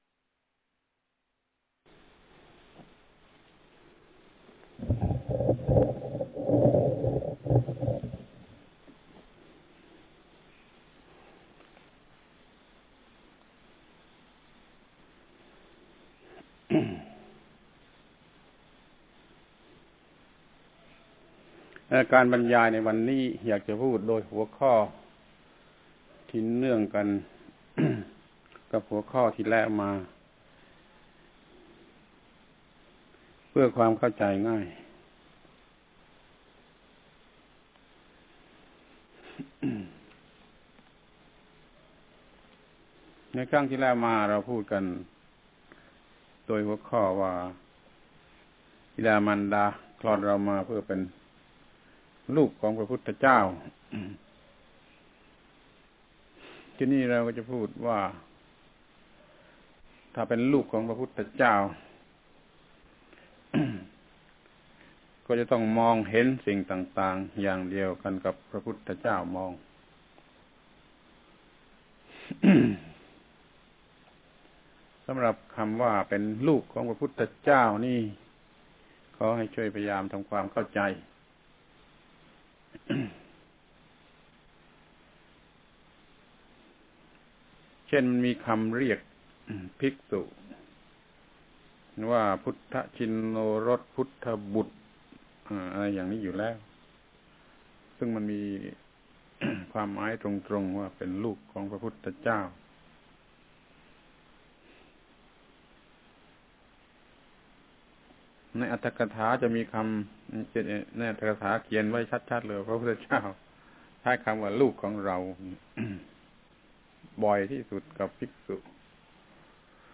การบรรยายในวันนี้ อยากจะพูดด้วยหัวข้อที่เนื่องกันกับหัวข้อที่แล้วมา เพื่อความเข้าใจง่าย ในครั้งที่แล้วมาเราพูดกันด้วยหัวข้อว่า บิดา มารดาคลอดเรามาเพื่อที่จะเป็ ...